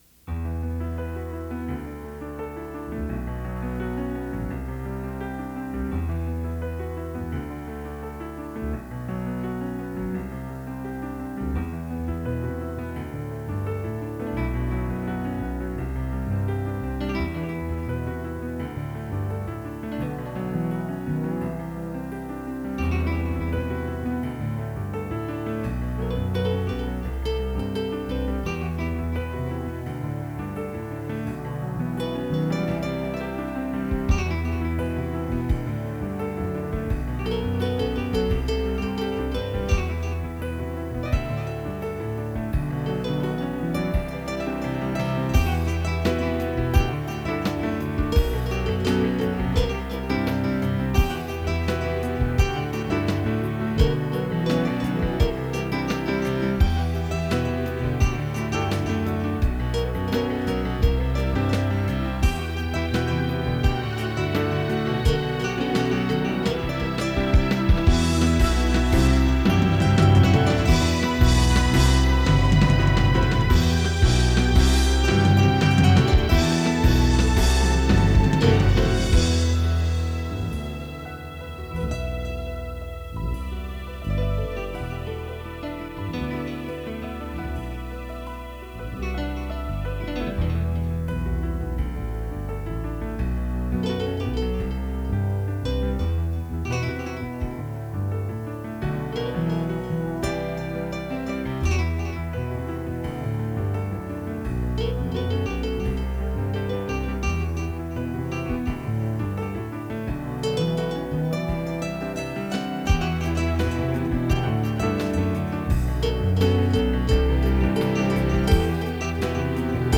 Mostly done with the M1 and SC-88.